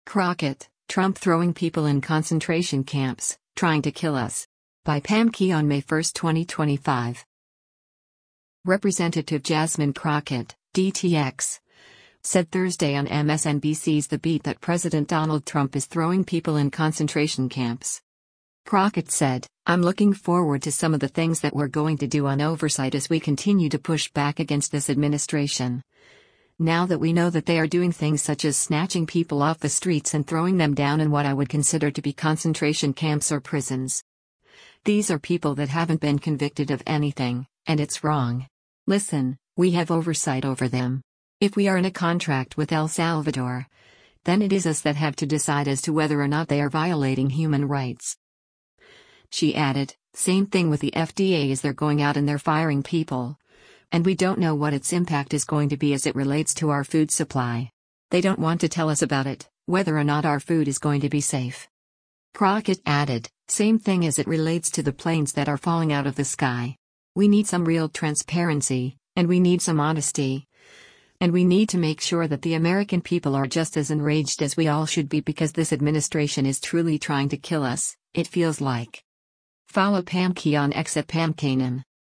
Representative Jasmine Crockett (D-TX) said Thursday on MSNBC’s “The Beat” that President Donald Trump is throwing people in “concentration camps.”